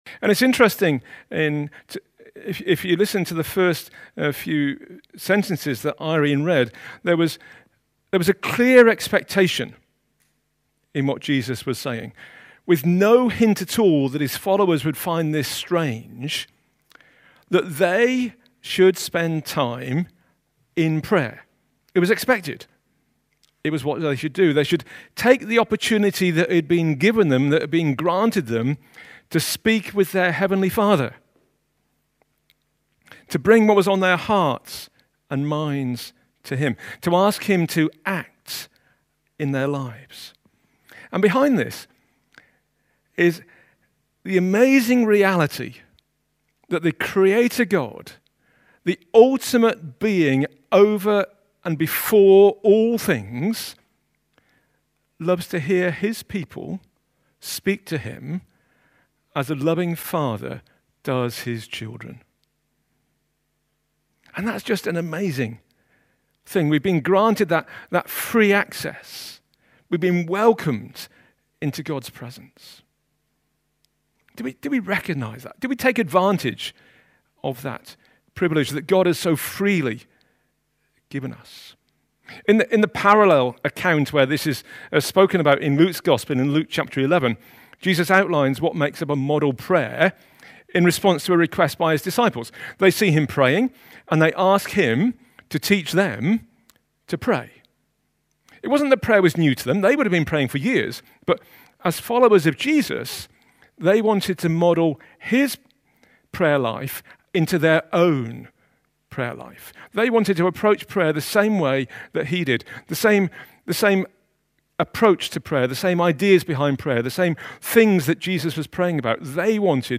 A talk from the series "The Sermon on the Mount."